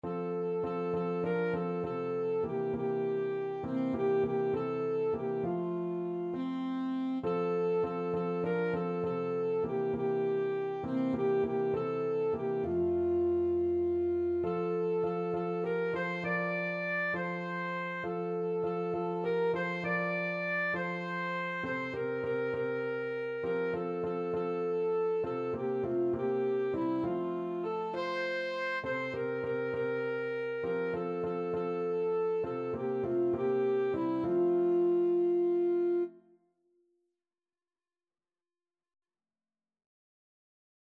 Alto Saxophone
6/8 (View more 6/8 Music)